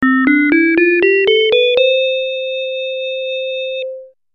3.鉄琴の様な音色
crfmvibraphone.mp3